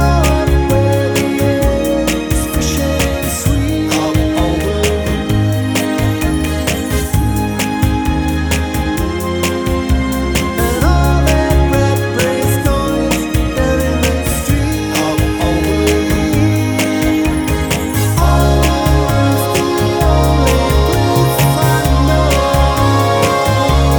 For Solo Singer Pop (1990s) 2:39 Buy £1.50